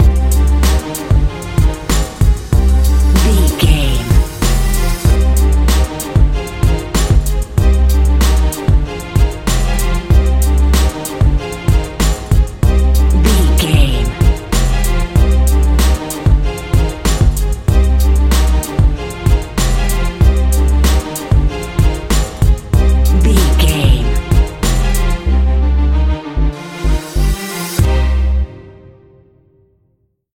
Aeolian/Minor
synthesiser
strings
hip hop
Funk